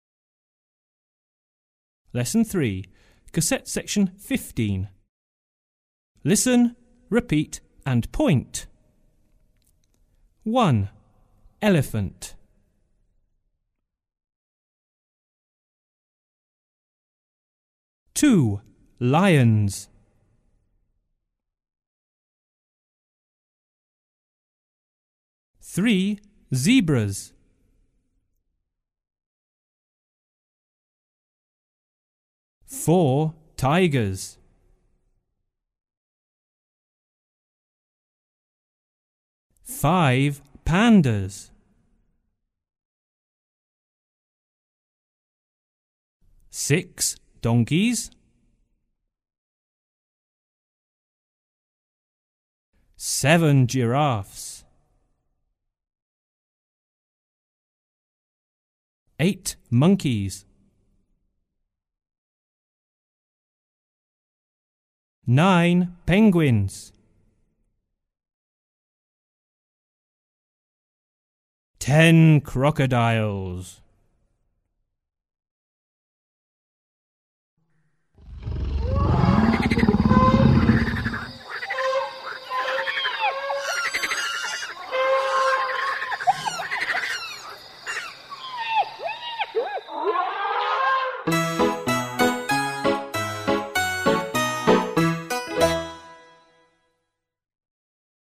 Texty sú nahovorené rodeným Angličanom, piesne spievajú deti slovenských škôl.